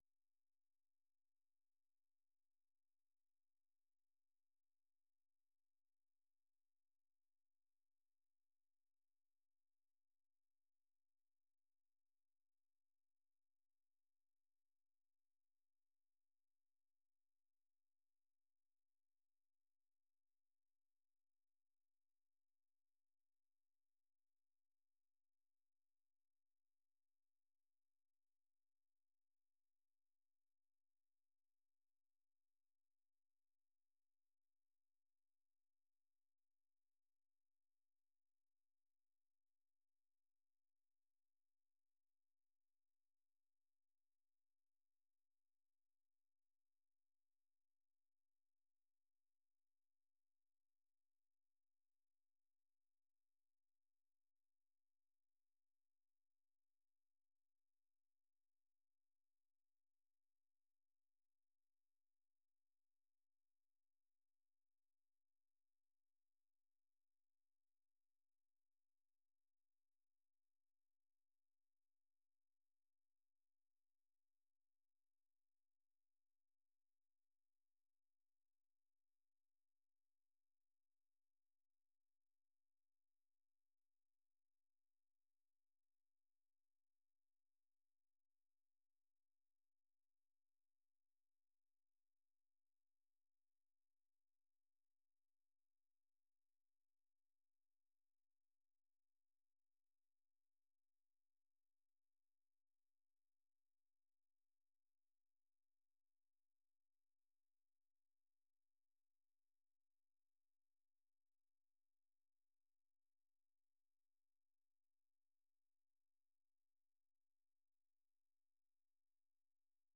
The URL has been copied to your clipboard 페이스북으로 공유하기 트위터로 공유하기 No media source currently available 0:00 0:59:58 0:00 생방송 여기는 워싱턴입니다 생방송 여기는 워싱턴입니다 저녁 공유 생방송 여기는 워싱턴입니다 저녁 share 세계 뉴스와 함께 미국의 모든 것을 소개하는 '생방송 여기는 워싱턴입니다', 저녁 방송입니다.